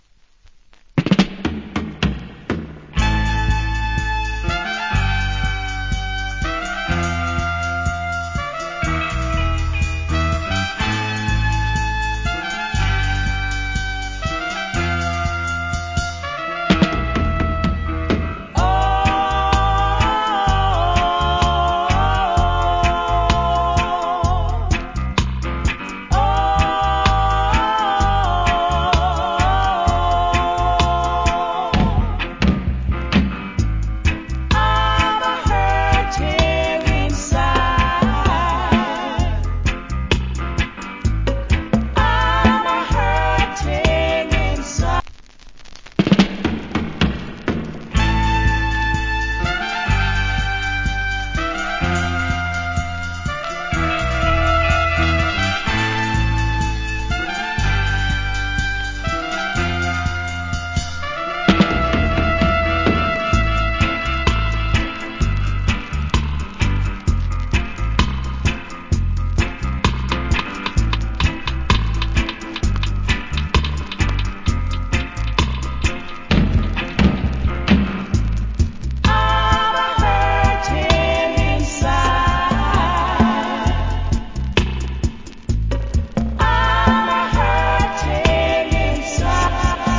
Killer Female Reggae Vocal.